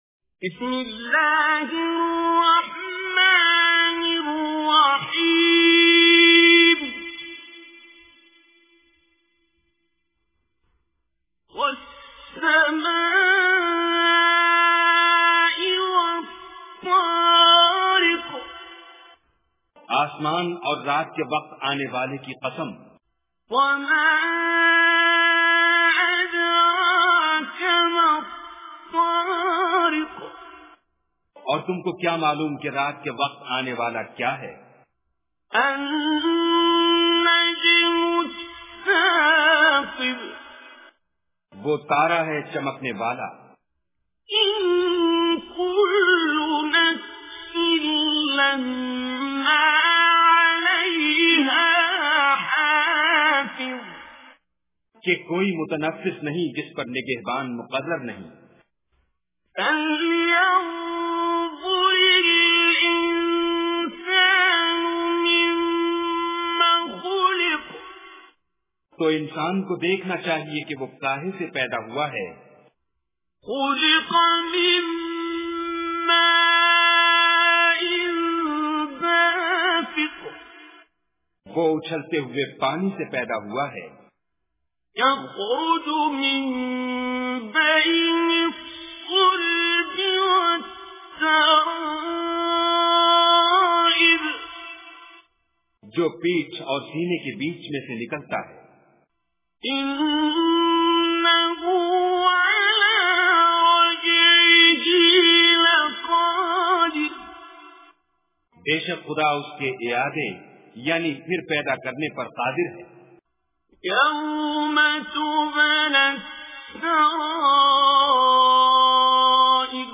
Surah Tariq Recitation with Urdu Translation
Surah Tariq is 86th chapter of Holy Quran. Listen online and download mp3 tilawat / recitation of Surah Tariq in the beautiful voice of Qari Basit As Samad.